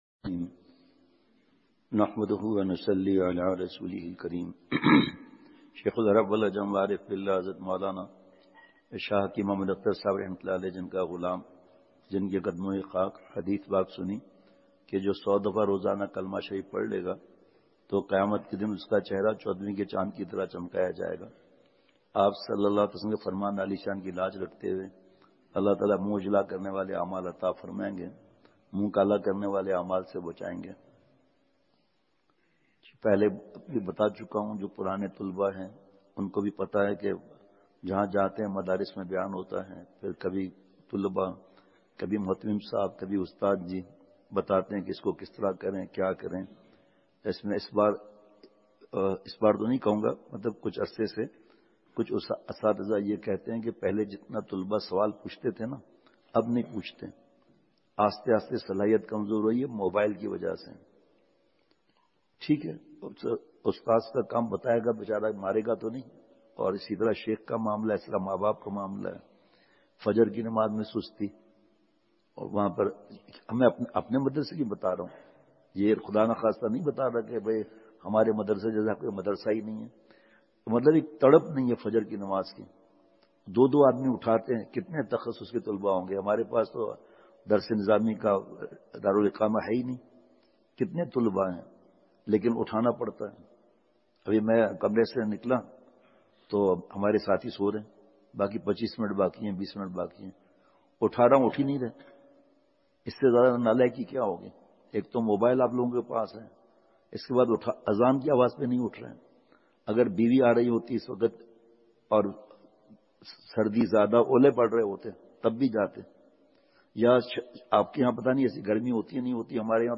بمقام۔ جامعہ تعلیم القرآن تختہ بند سوات
بعد فجر بیان